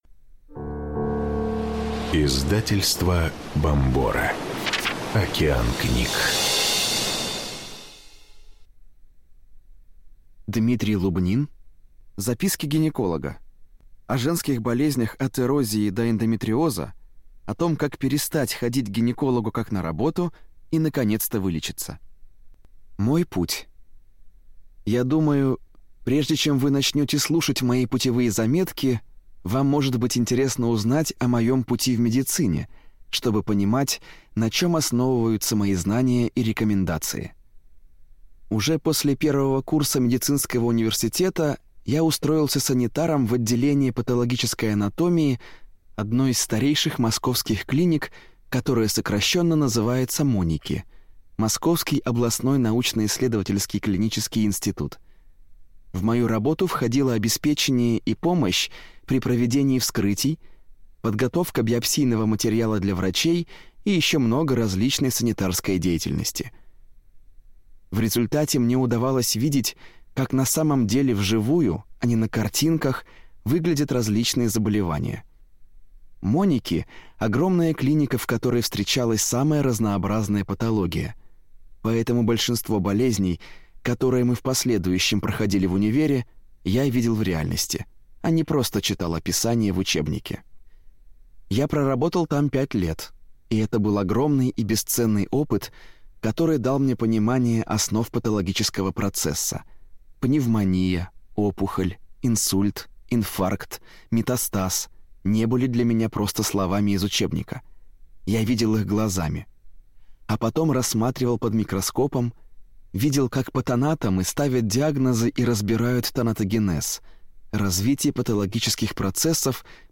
Аудиокнига Записки гинеколога: о женских болезнях от эрозии до эндометриоза, о том, как перестать ходить к гинекологу «как на работу» и наконец-то вылечиться | Библиотека аудиокниг